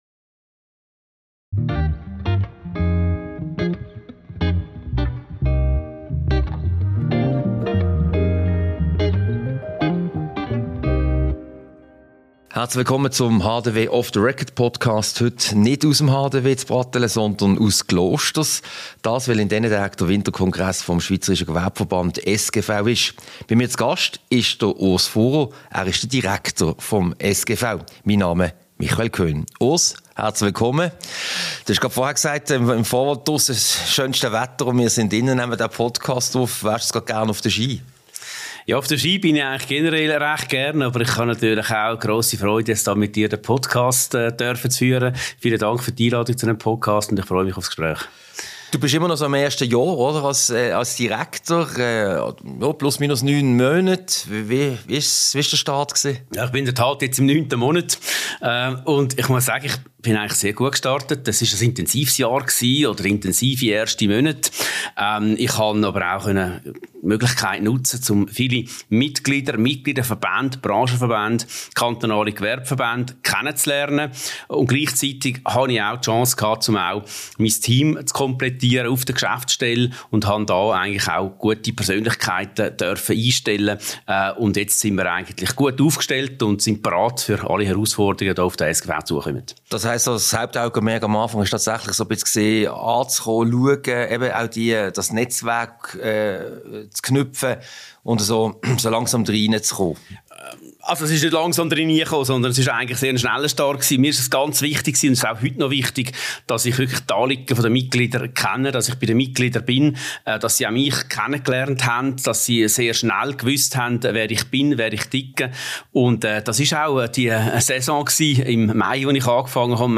Diese Podcast-Ausgabe wurde als in Klosters aufgezeichnet, anlässlich des Winterkongresses des Schweizerischen Gewerbeverbandes SGV.